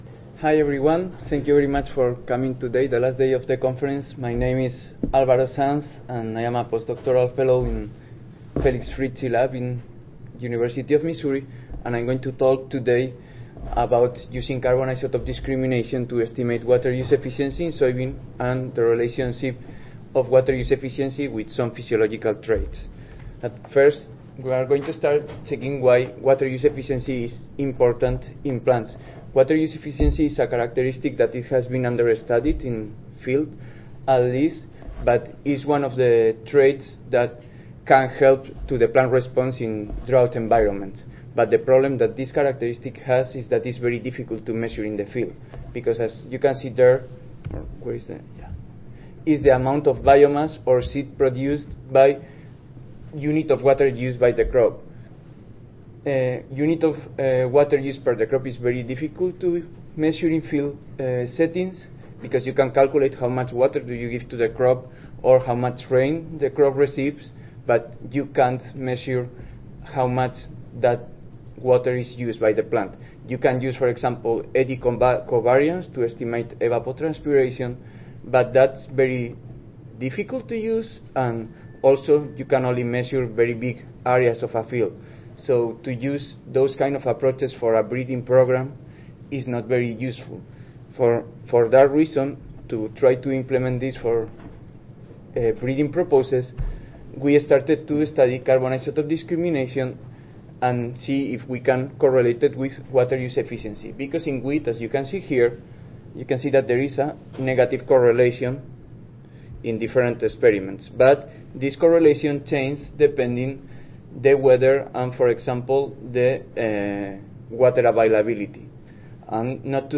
See more from this Division: C02 Crop Physiology and Metabolism See more from this Session: Crop Physiology and Metabolism General Oral III